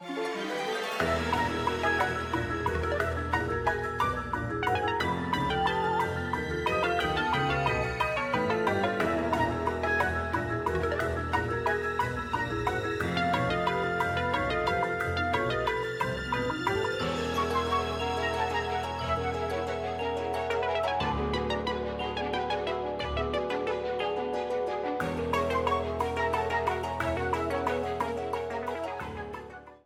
A theme
Ripped from the game
clipped to 30 seconds and applied fade-out